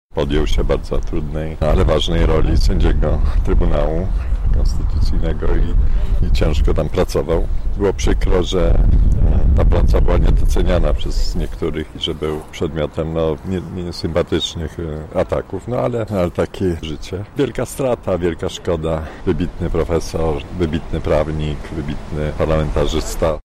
Ryszard Terlecki, przewodniczący klubu Parlamentarnego PiS mówi o wybitności profesora.